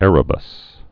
(ĕrə-bəs)